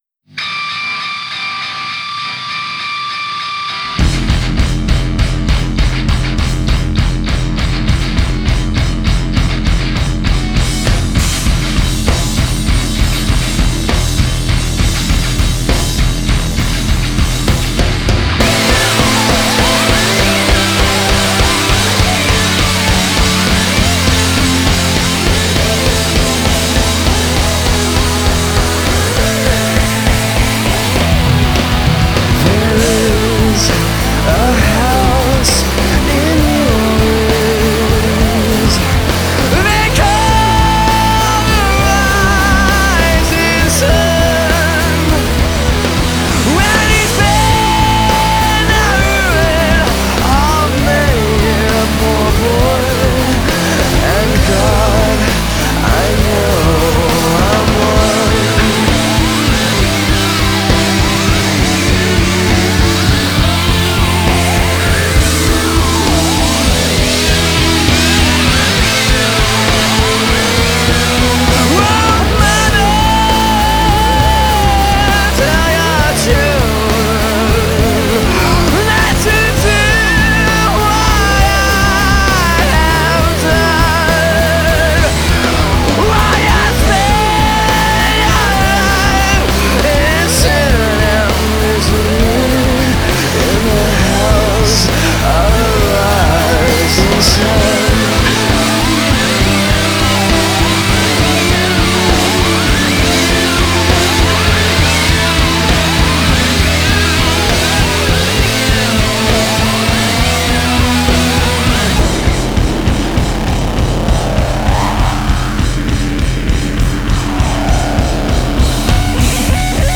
Alternative/Indie progressive rock Hard Rock